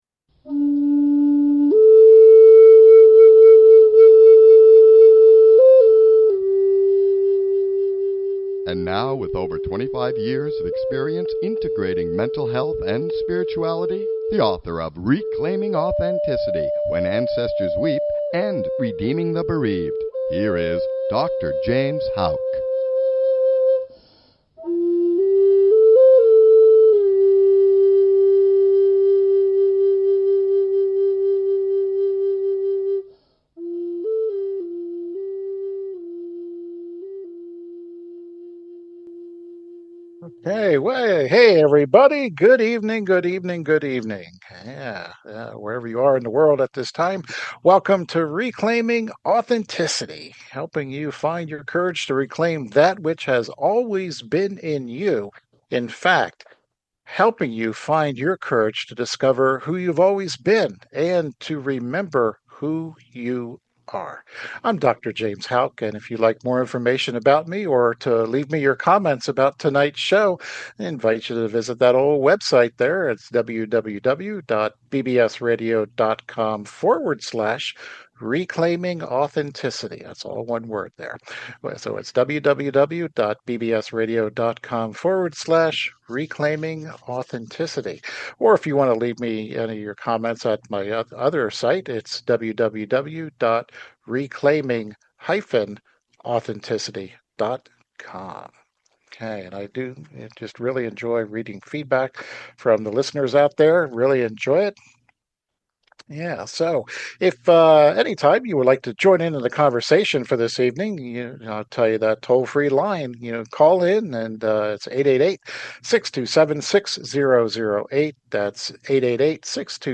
Talk Show Episode, Audio Podcast, Reclaiming Authenticity and Breaking the Golden Silence and the Concept of Han on , show guests , about Breaking the Golden Silence,the Concept of Han, categorized as Education,Health & Lifestyle,Love & Relationships,Psychology,Mental Health,Self Help,Society and Culture,Spiritual